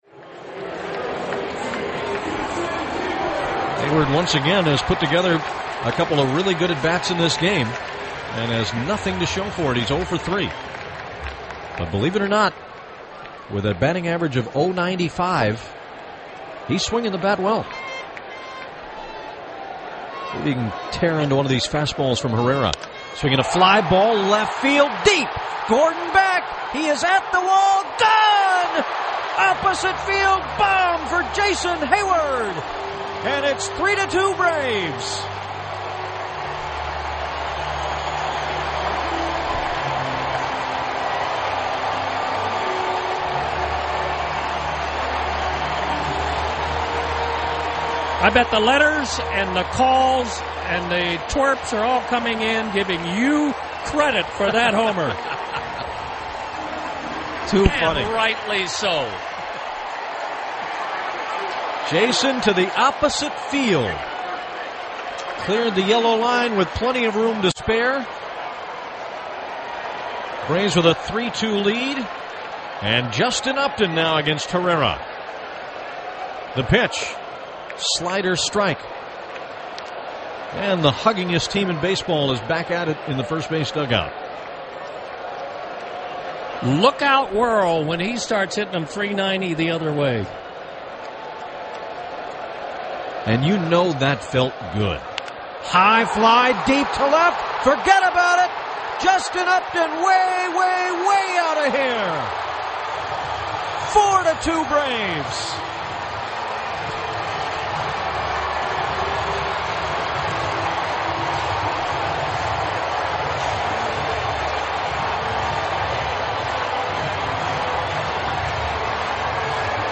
Jason Heyward and Justin Upton with back to back homers in the Braves 8th. Called by (and called by) Jim Powell and Don Sutton.